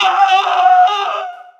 smm_scream.wav